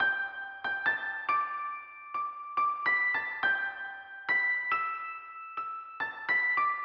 钢琴1
Tag: 140 bpm Rap Loops Piano Loops 1.15 MB wav Key : G